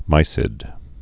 (mīsĭd)